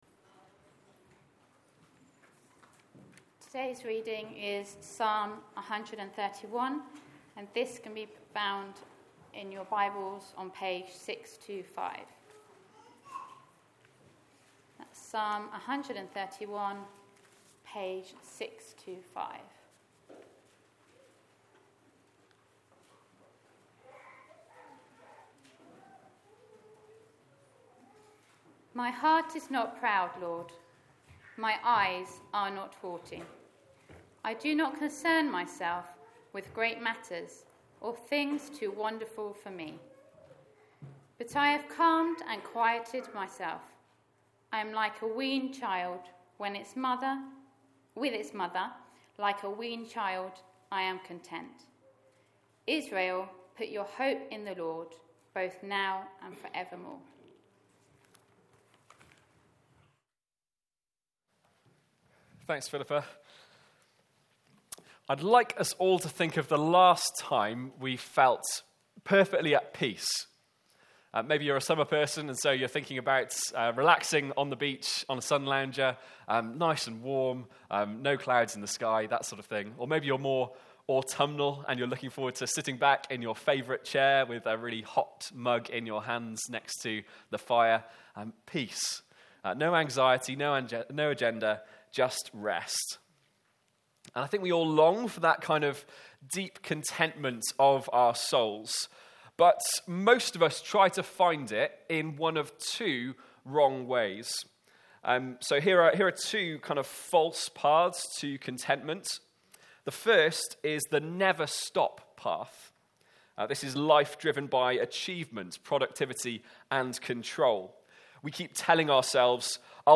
The Anatomy of the Soul Current Sermon